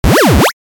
دانلود صدای موس 6 از ساعد نیوز با لینک مستقیم و کیفیت بالا
جلوه های صوتی
برچسب: دانلود آهنگ های افکت صوتی اشیاء دانلود آلبوم صدای کلیک موس از افکت صوتی اشیاء